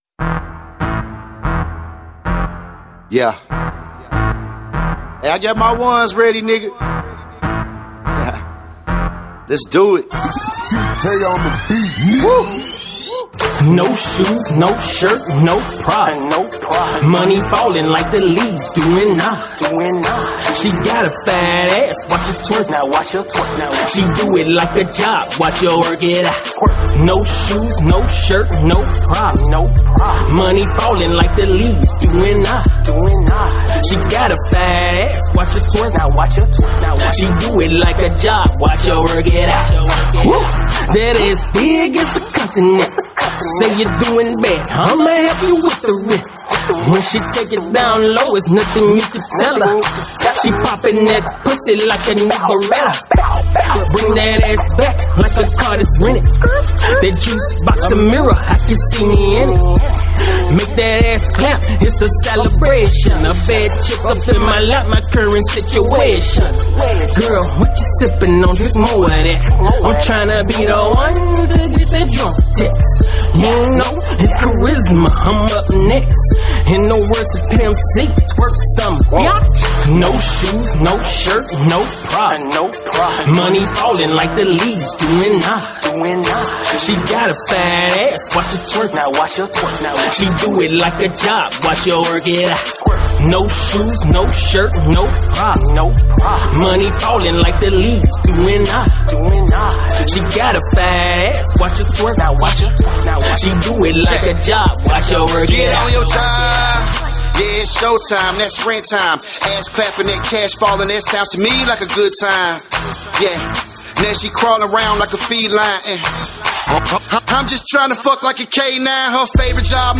Description : Club Track Prod.